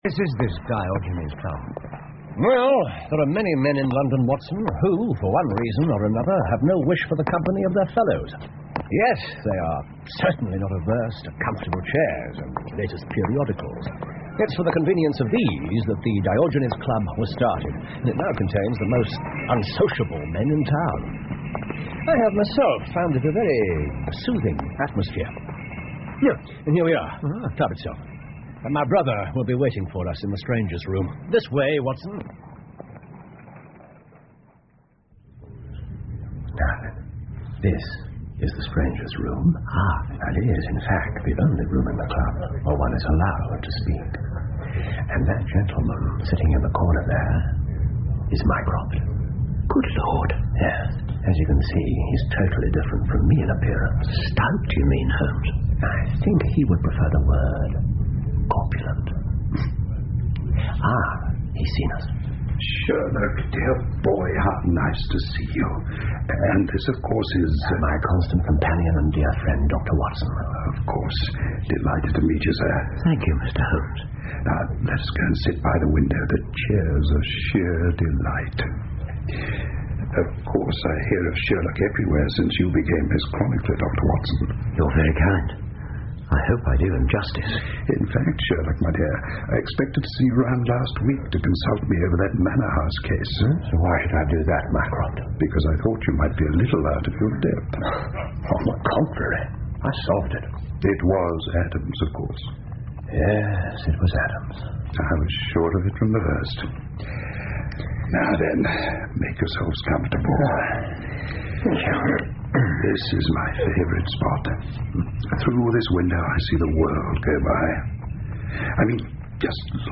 福尔摩斯广播剧 The Greek Interpreter 2 听力文件下载—在线英语听力室